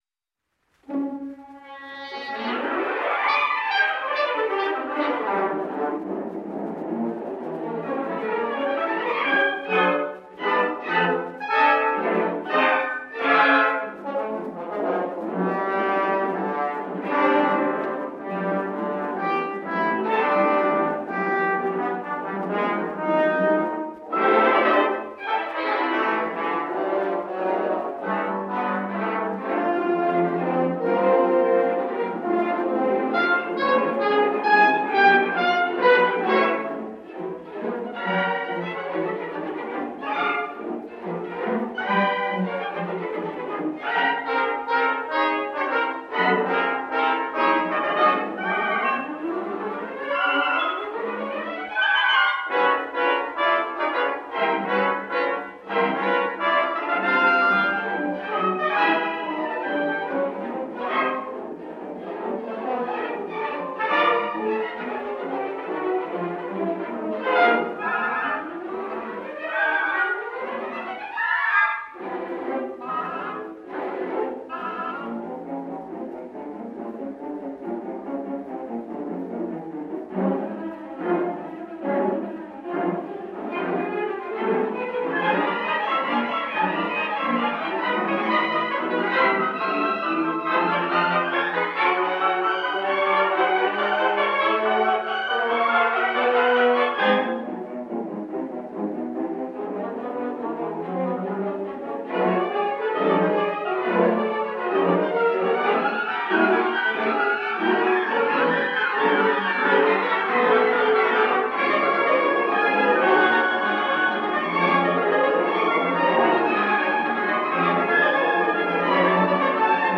Grand Orchestre Symphonique under the direction of Arthur Honegger. (1929 recording / Odéon)